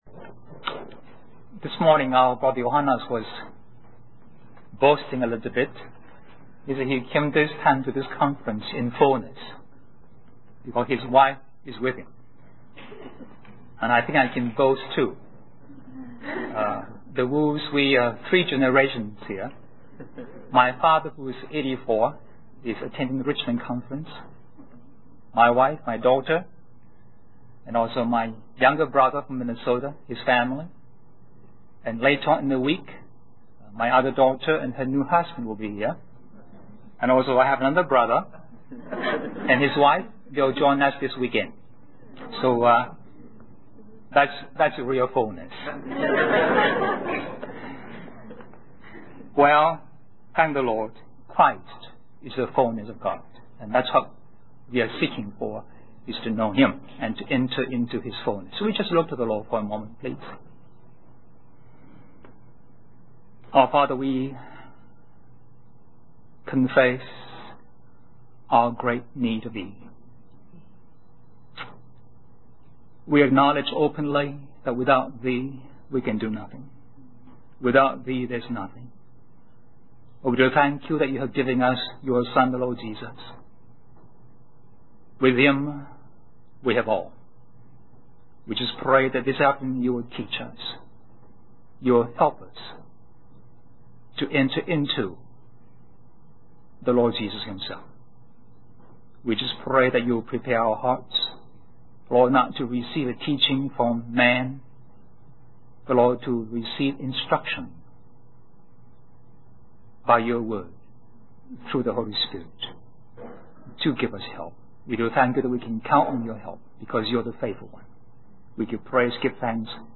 In this sermon, the speaker emphasizes the importance of discipline in various aspects of life. He uses the example of musicians who must practice and give their all to perform well.